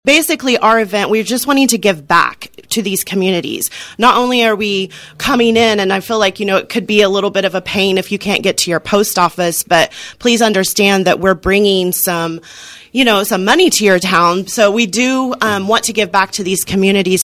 With Unbound Gravel fast approaching, organizers detail course information, volunteer needs on KVOE Morning Show | KVOE